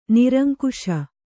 ♪ niraŋkuśa